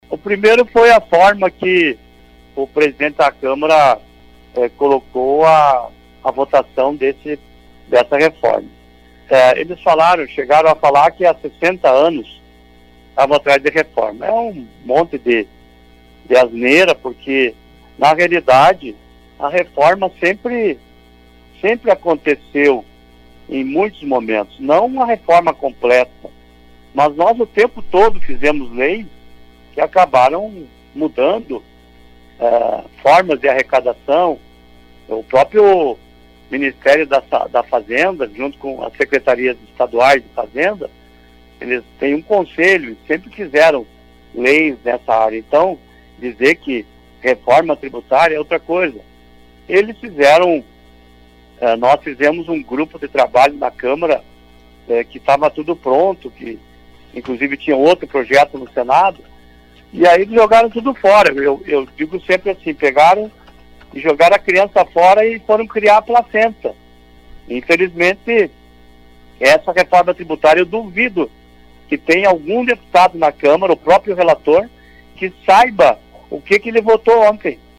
A aprovação da reforma tributária em primeiro turno concluída na madrugada de hoje foi duramente criticada pelo deputado federal Giovani Cherini durante entrevista ao Fatorama.